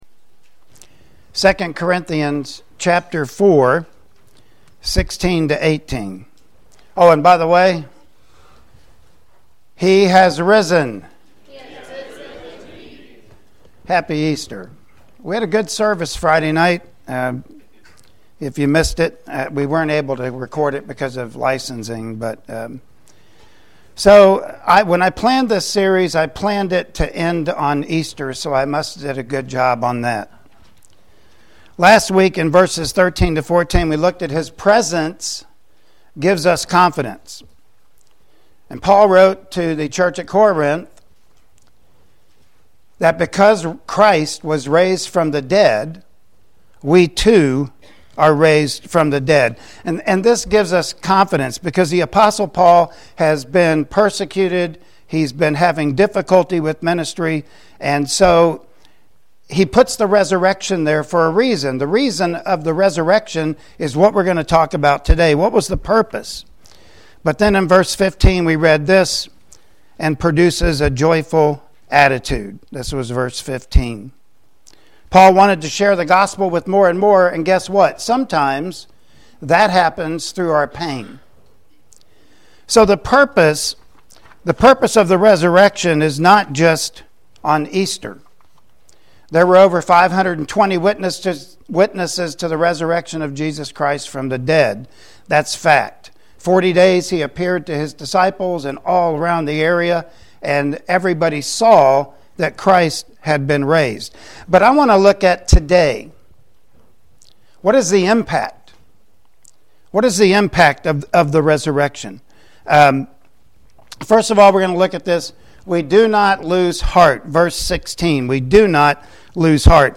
Passage: 2 Cortinthians 4:16-18 Service Type: Sunday Morning Worship Service